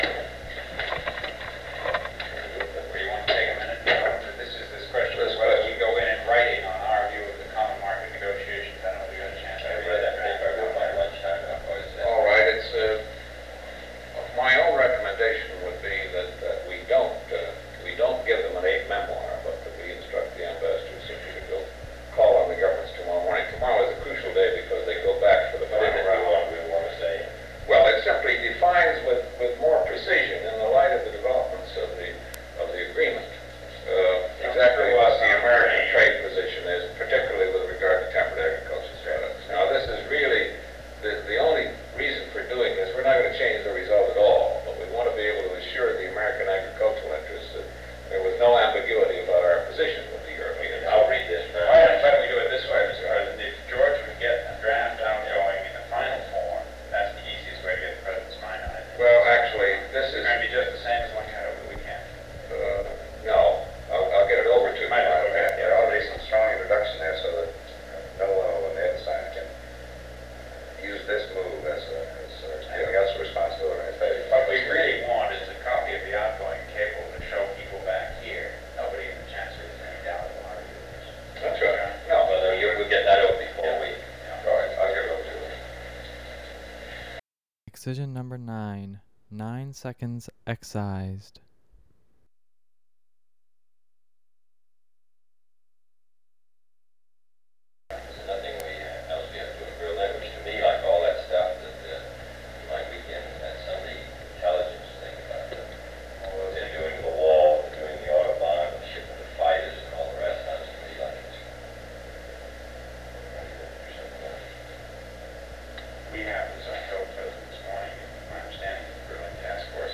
Secret White House Tapes | John F. Kennedy Presidency Meeting on Europe and General Diplomatic Matters Rewind 10 seconds Play/Pause Fast-forward 10 seconds 0:00 Download audio Previous Meetings: Tape 121/A57.